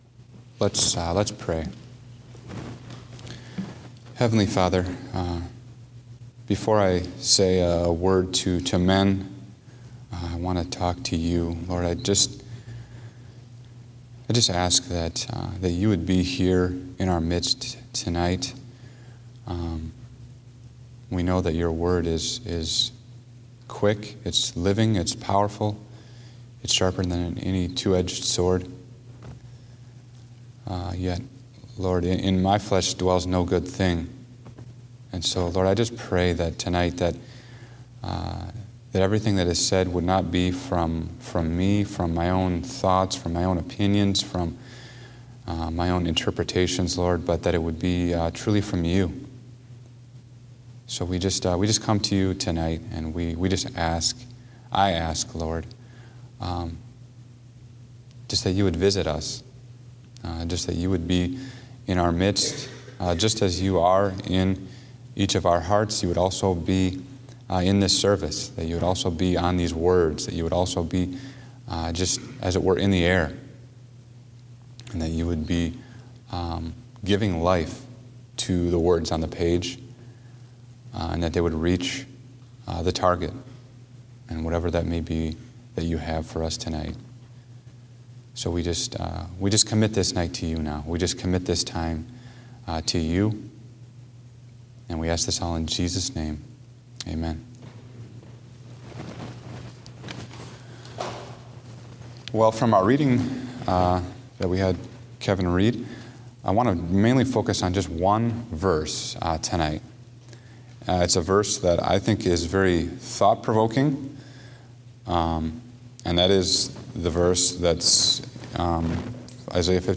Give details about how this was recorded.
Date: May 29, 2016 (Evening Service)